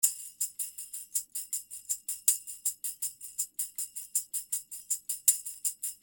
80 BPM tambourine (6 variations)
Tambourine loops in 6 variations playing in 80 bpm.